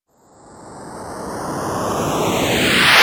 VEC3 Reverse FX
VEC3 FX Reverse 03.wav